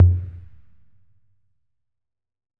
Tom_C3.wav